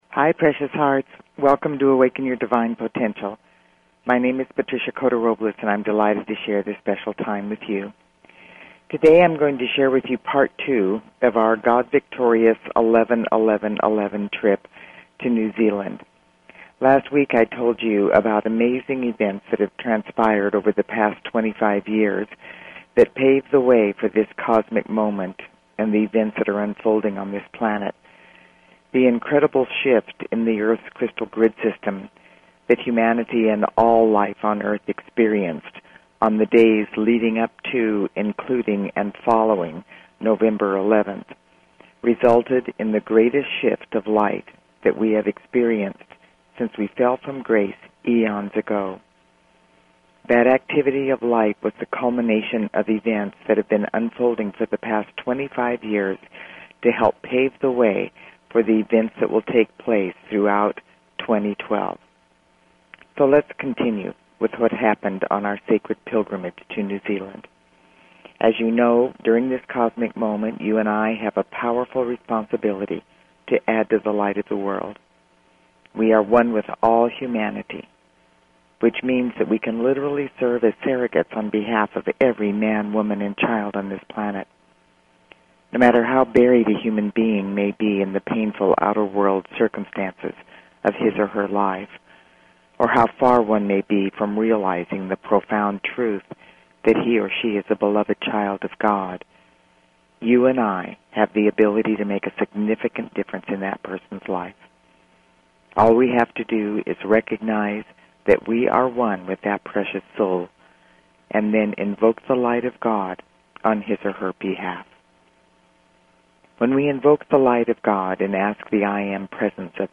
Talk Show Episode, Audio Podcast, Awaken_Your_Divine_Potential and Courtesy of BBS Radio on , show guests , about , categorized as